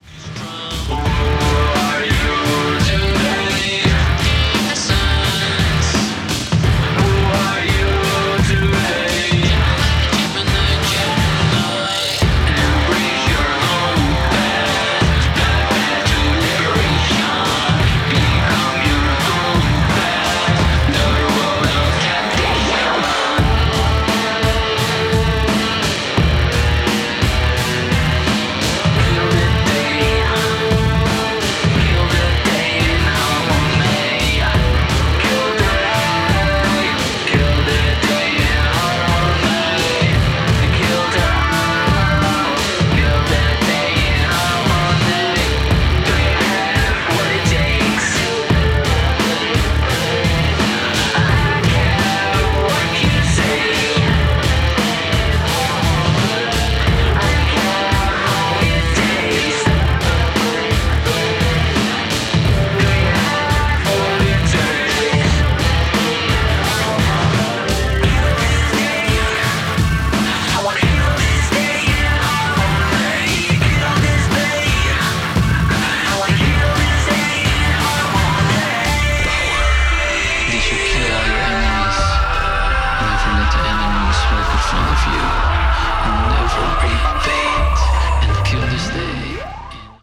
noise, punk, industrial, electronic, and even pop